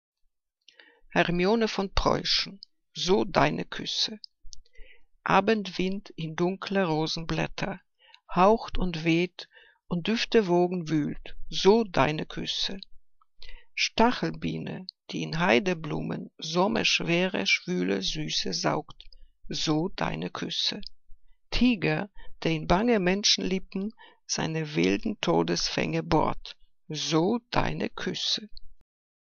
Liebeslyrik deutscher Dichter und Dichterinnen - gesprochen (Hermione von Preuschen)